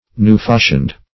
Newfashioned \New`fash"ioned\, a.